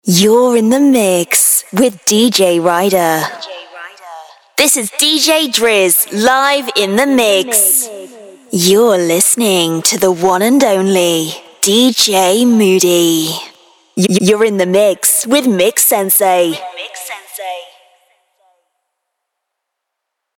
British Female DJ Drops
Stand out with our personalized UK female DJ drops, professionally recorded by a British voice artist. Authentic accent.
2026-British-female-drops.mp3